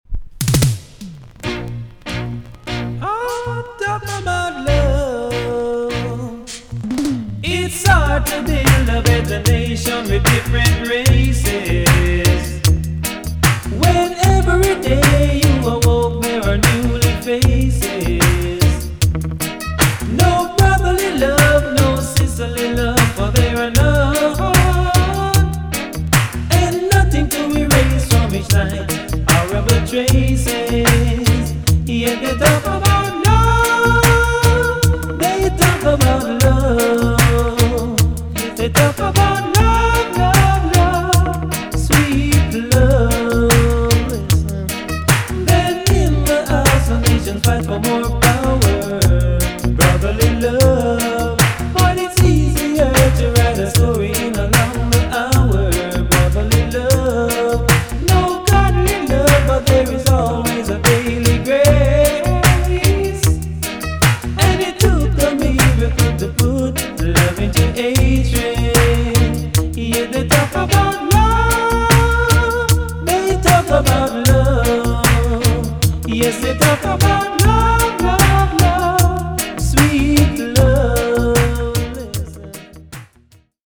TOP >DISCO45 >80'S 90'S DANCEHALL
EX- 音はキレイです。